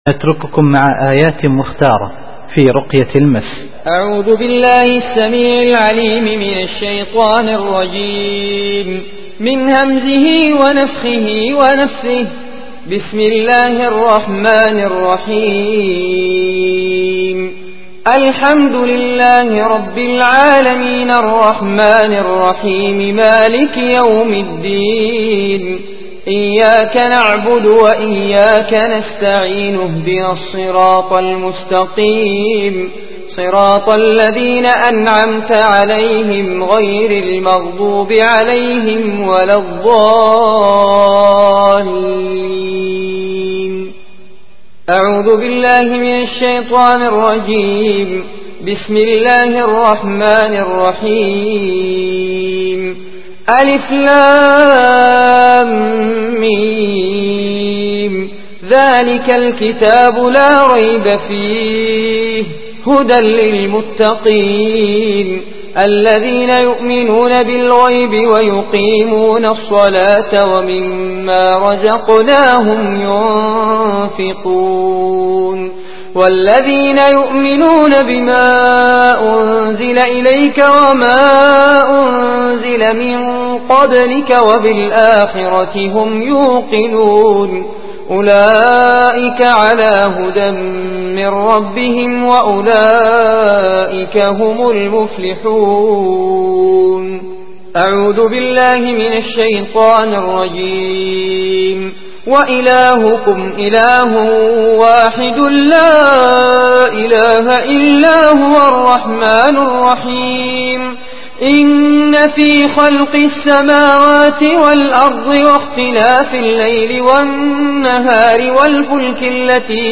الرقية الشرعية - رقية المس - المكتبة الإسلامية